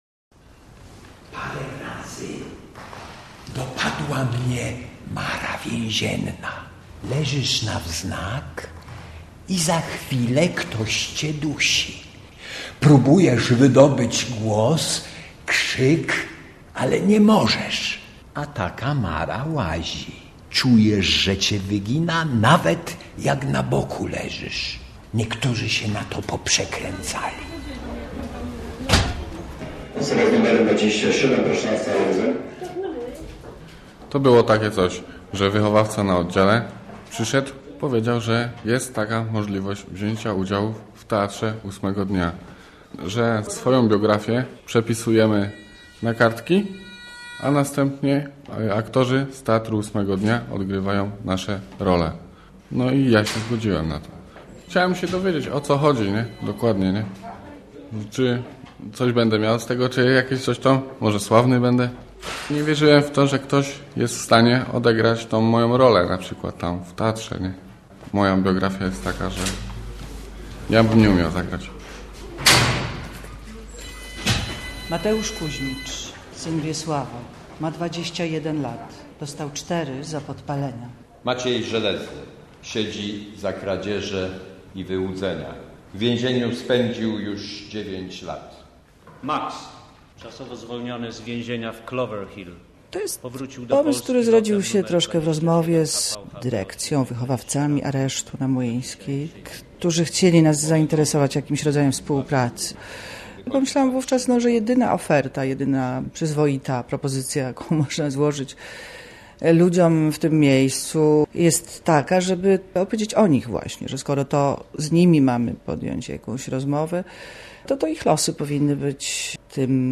Osadzeni. Młyńska 1 - reportaż